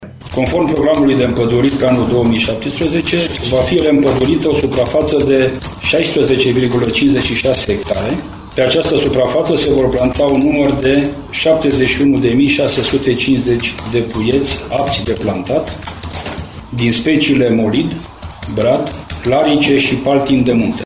Primarul municipiului Săcele a anunțat, în cadrul unei conferințe de presă, debutul campaniei de împădurire: